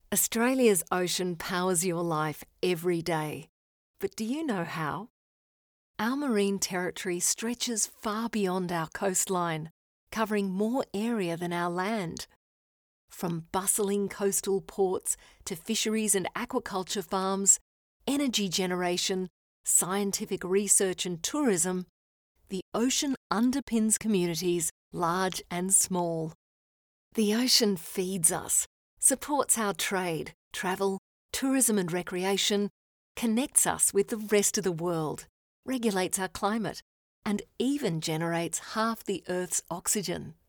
I'm an Australian voice over artist with a naturally warm, smooth sound. My voice is often described as calm and approachable, with a touch of sophistication and confidence. I have a broadcast-quality home studio and love working across all kinds of projects – from commercials to narration to e-learning - bringing clarity and heart to every read.
Words that describe my voice are Warm, Articulate, Genuine.